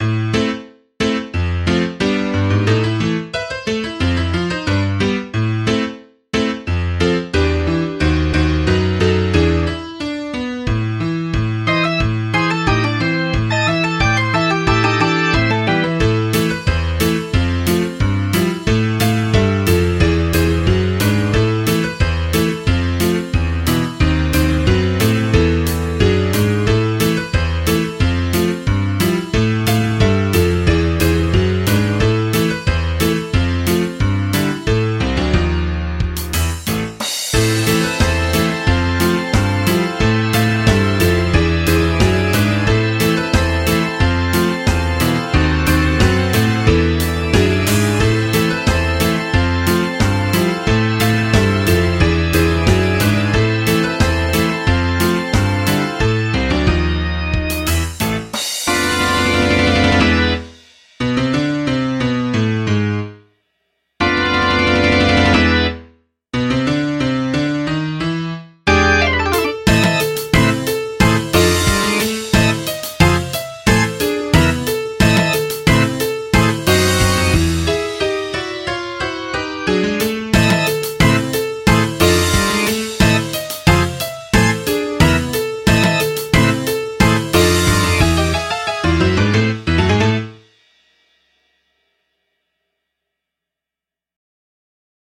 MIDI 11.67 KB MP3